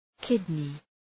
Προφορά
{‘kıdnı}